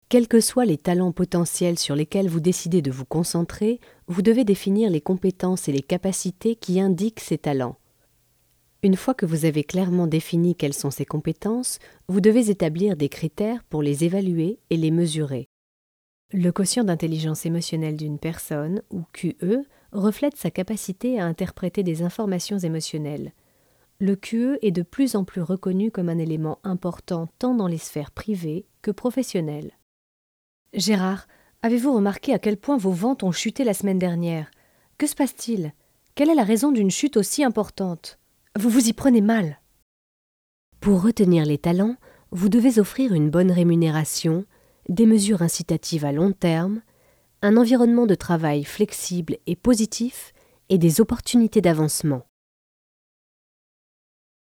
Tous types de voix pour e-learning, institutionnel, promo douce.
Sprechprobe: Industrie (Muttersprache):
My voice is perfect for smooth commercials or institutional recordings.